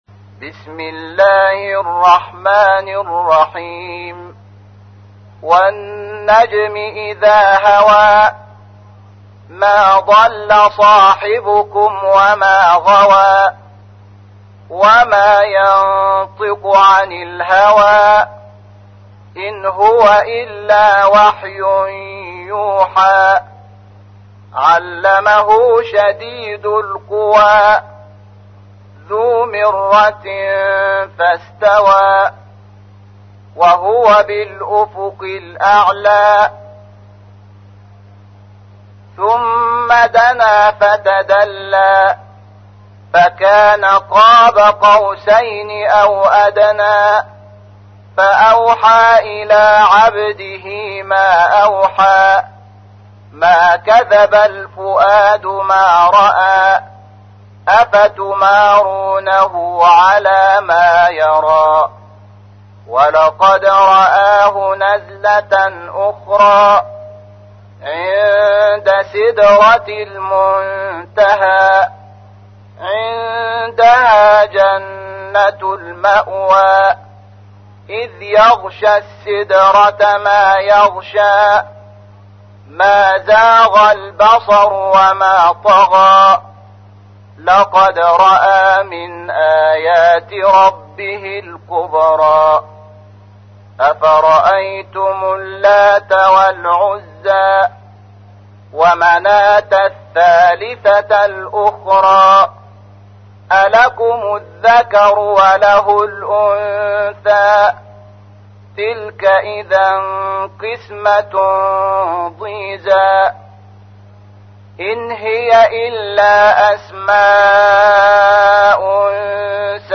تحميل : 53. سورة النجم / القارئ شحات محمد انور / القرآن الكريم / موقع يا حسين